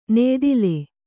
♪ neydile